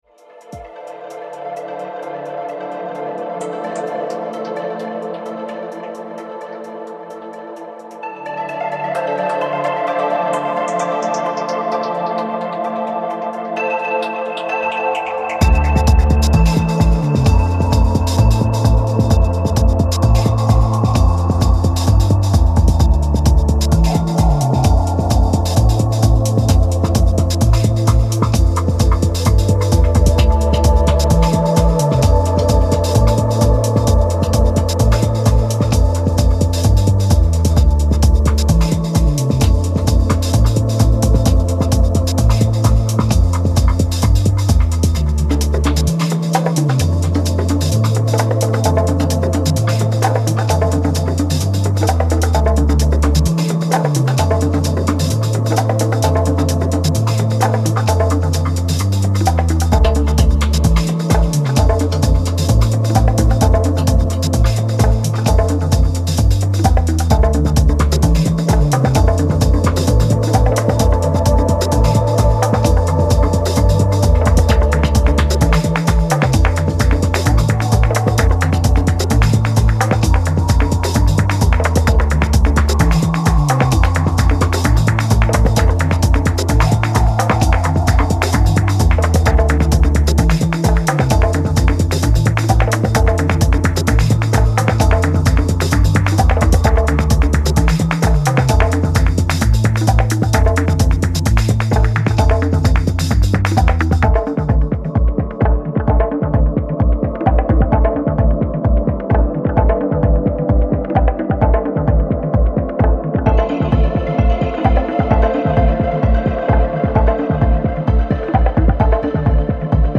groovy, adventurous & playful dance music